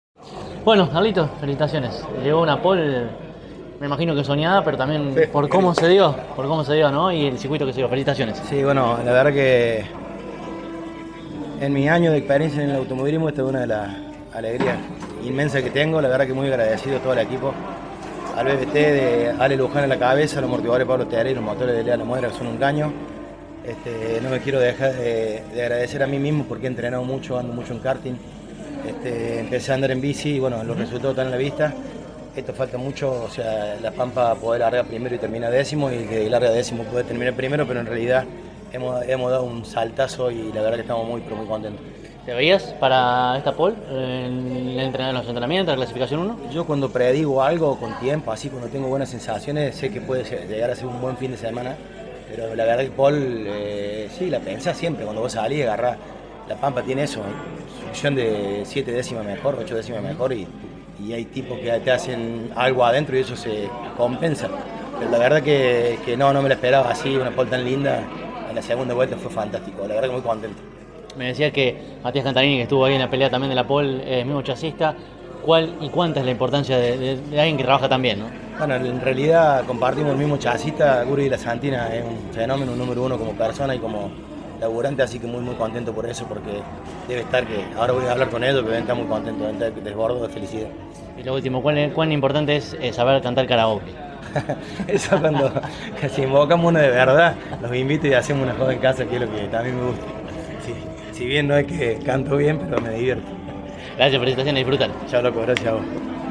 en diálogo con CÓRDOBA COMPETICIÓN